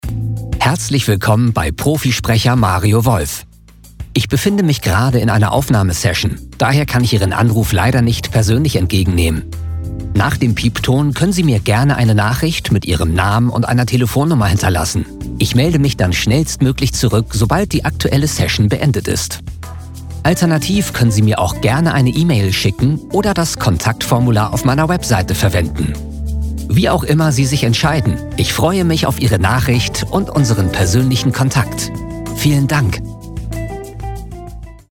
Kommerziell, Cool, Erwachsene, Freundlich, Corporate
Telefonie